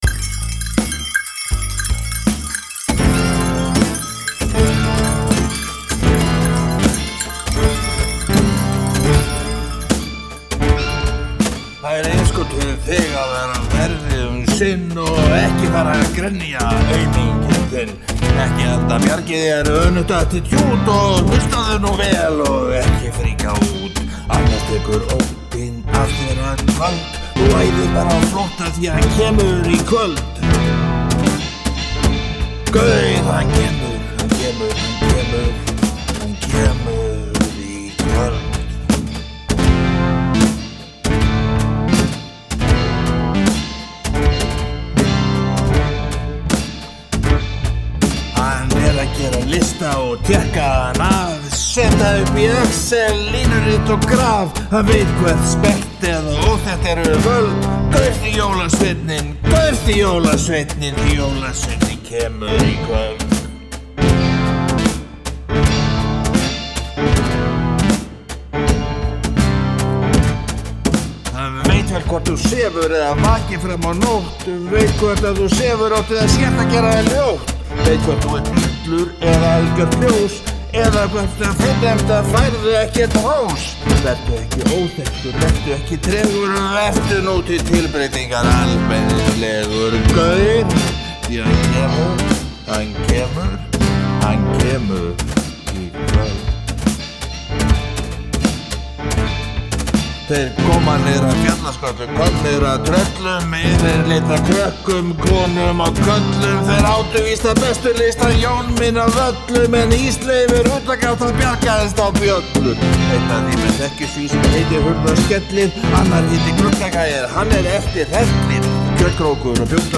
Söngur
Bassi